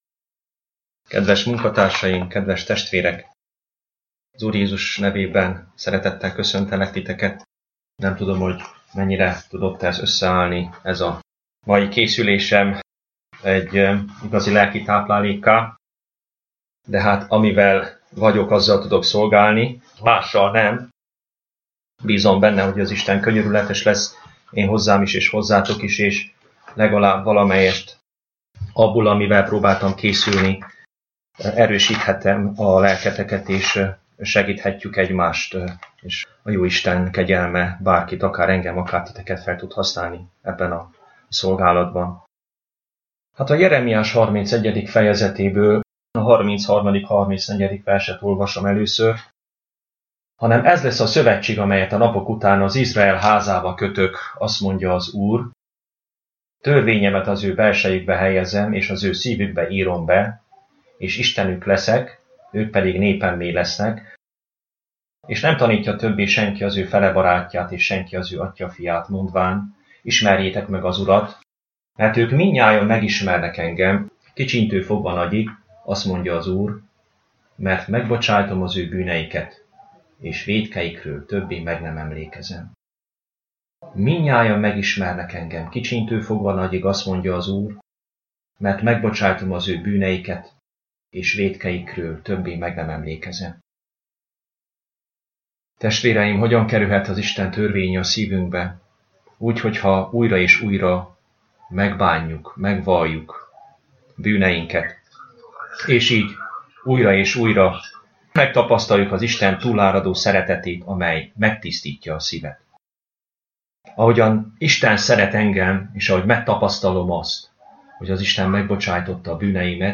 Az áhitat / elmélkedés meghallgatására kattints ide: